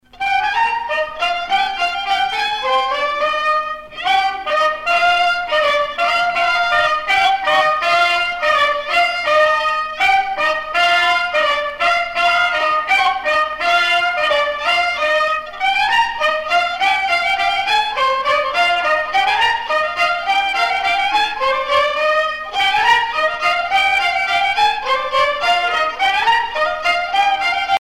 danse : bal à deux
Pièce musicale éditée